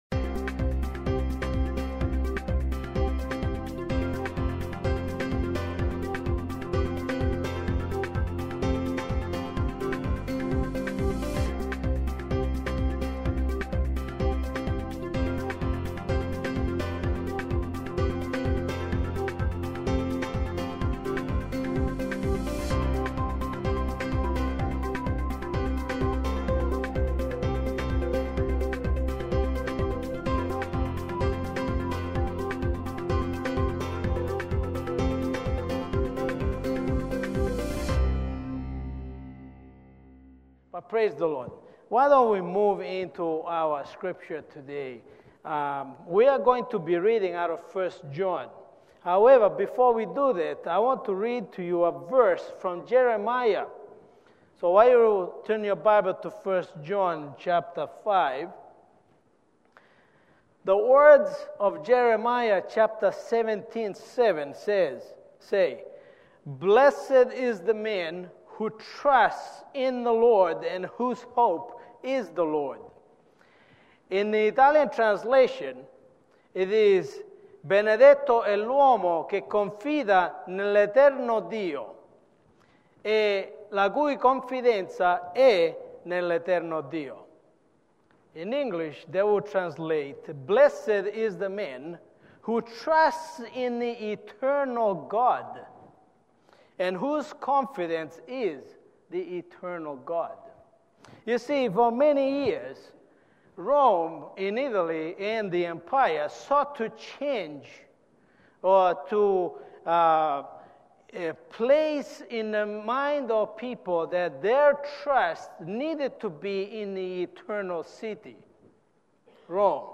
← Newer Sermon Older Sermon →